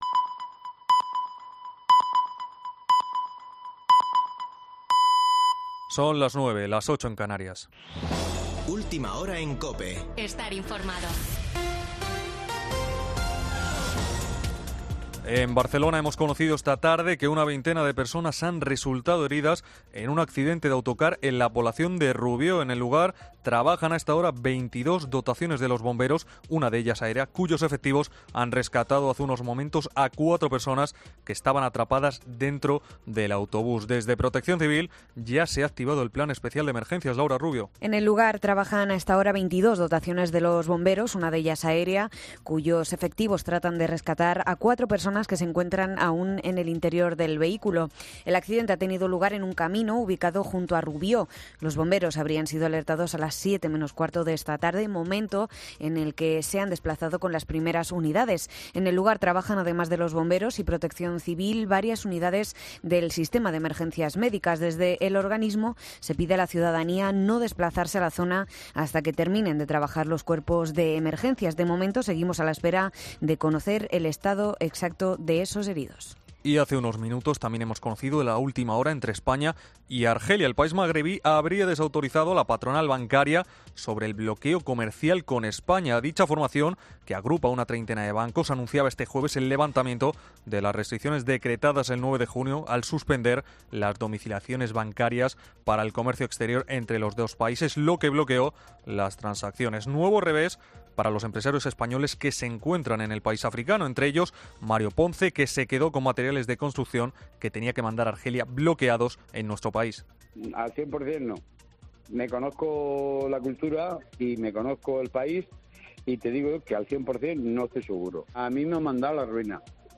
Boletín de noticias de COPE del 30 de julio de 2022 a las 21.00 horas